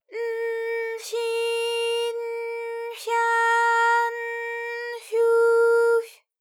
ALYS-DB-001-JPN - First Japanese UTAU vocal library of ALYS.
fy_n_fyi_n_fya_n_fyu_fy.wav